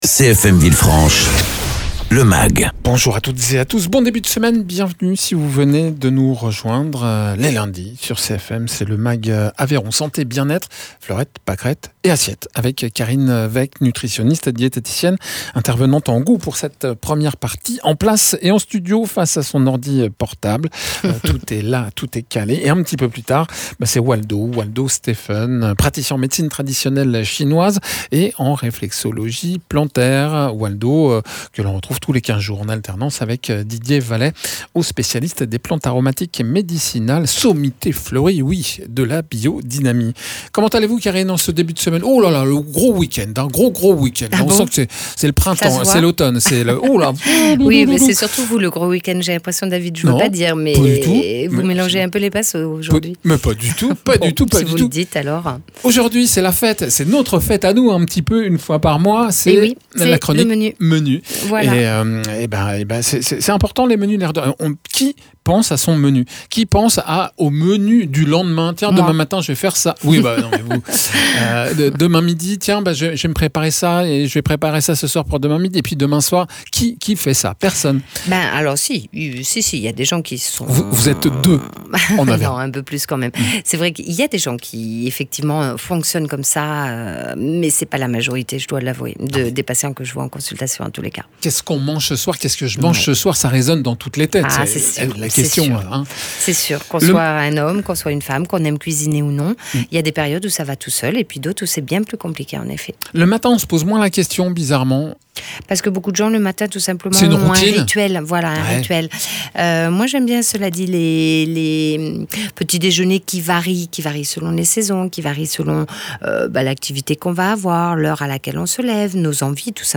nutritionniste diététicien
praticien en réflexologie plantaire et Médecine Traditionnelle Chinoise